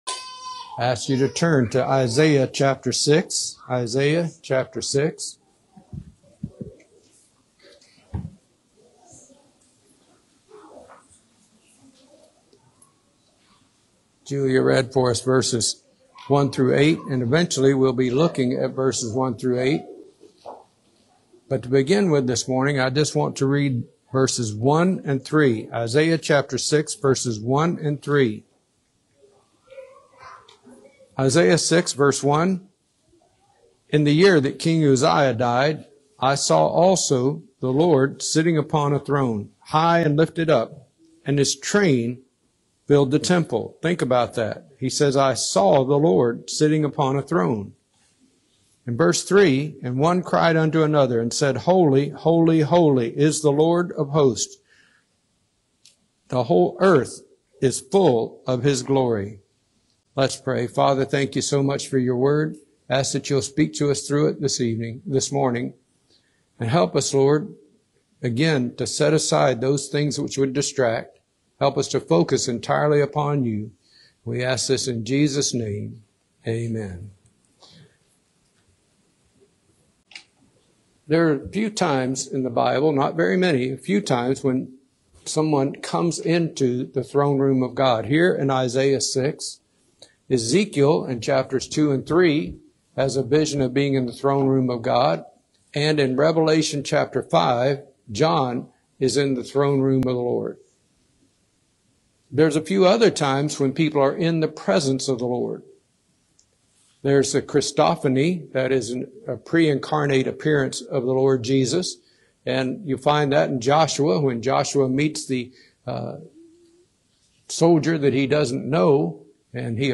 Drawing from Isaiah 6:1-8, the sermon explores how an encounter with the divine reshapes perspective and purpose.